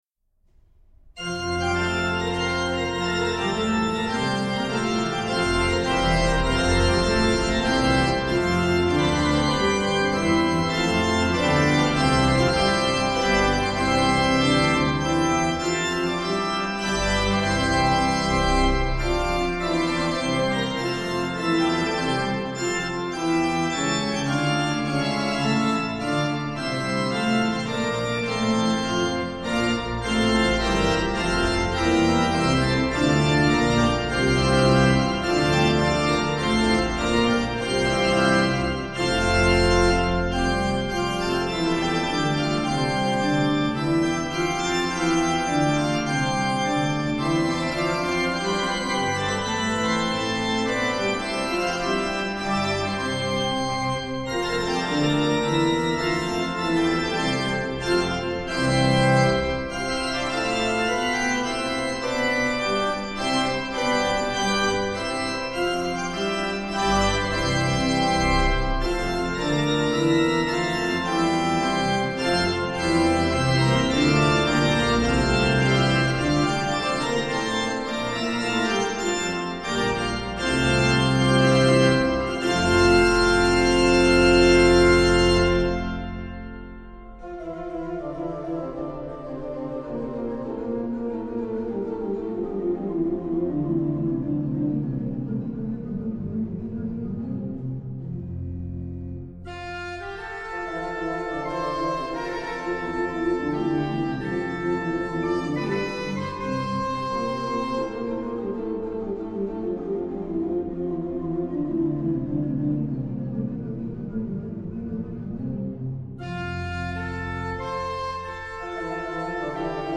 Die Schuke-Orgel in St. Stephani